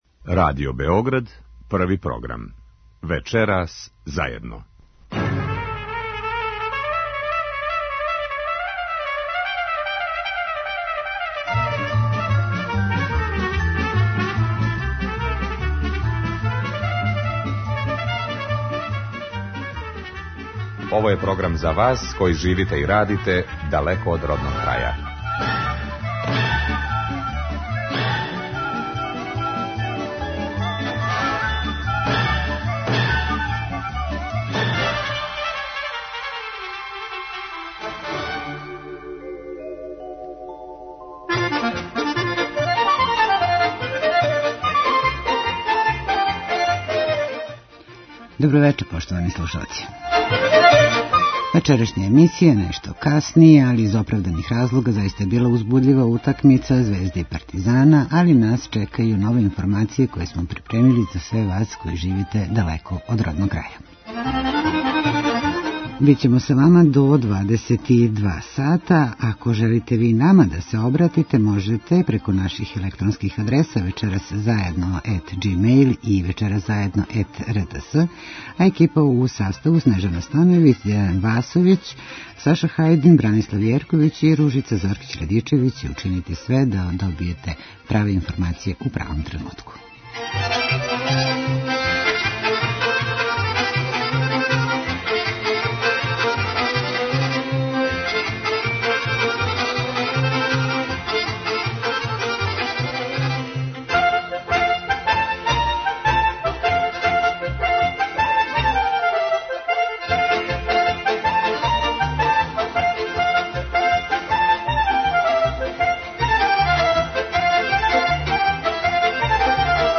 Интервју: министар Ђорђе Милићевић